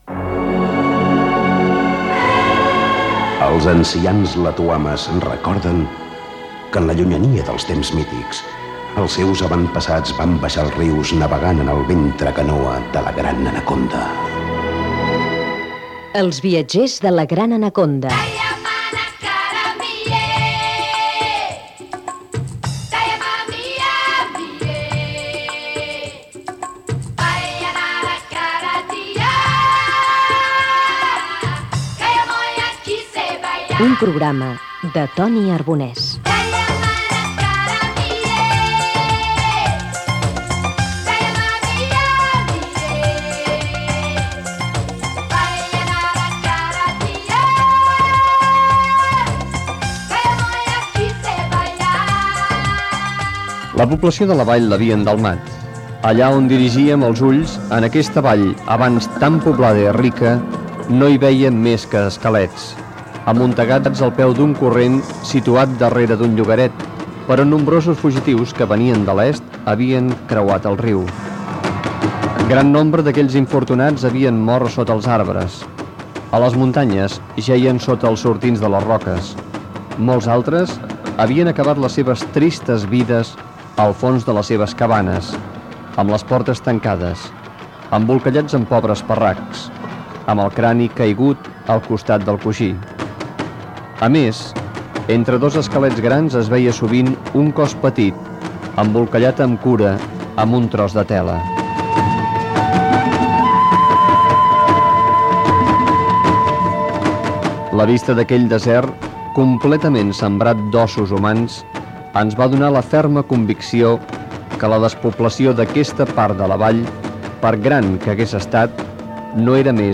Careta del programa, lectura d'un passatge d'un llibre de David Livingstone sobre el que va veure en una vall de Sud-Àfrica i l'esclavatge. Comentaris sobre els seus escrits i viatges per l'Àfrica